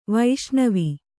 ♪ vaiṣṇavi